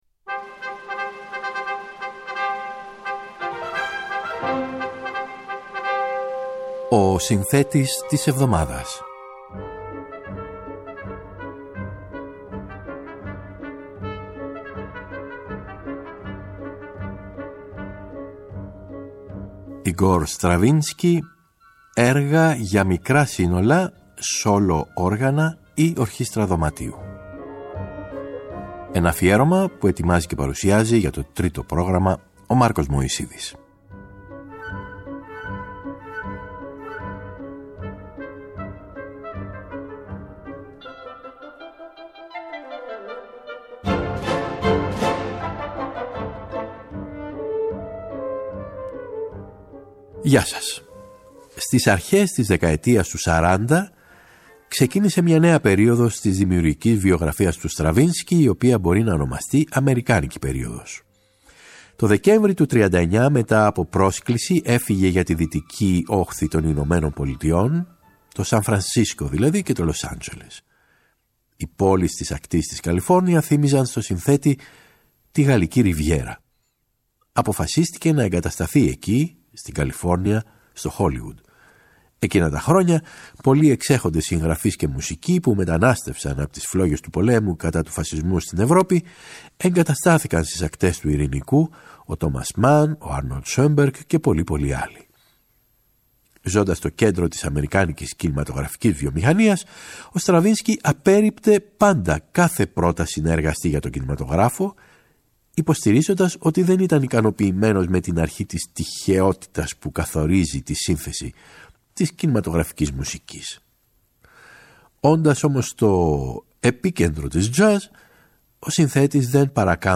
Έργα για μικρά σύνολα, σόλο όργανα, ή ορχήστρα δωματίου.